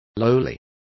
Complete with pronunciation of the translation of lowliest.